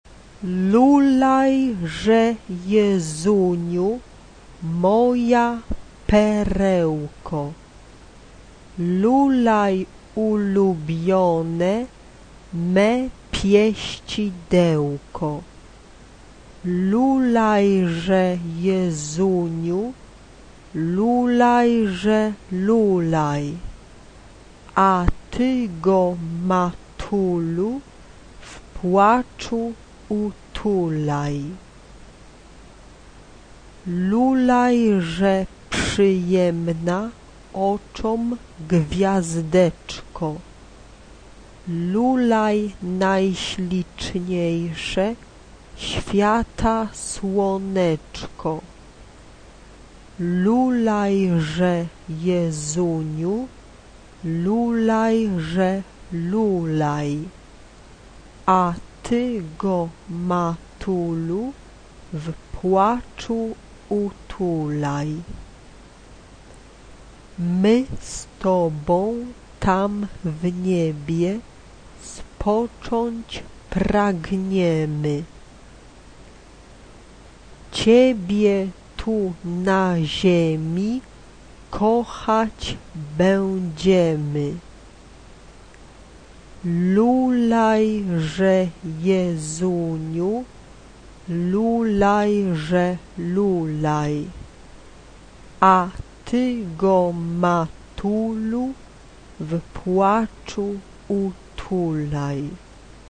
Sacred. Secular. Christmas song.
Christmas song
SATB (4 mixed voices )
Tonality: F major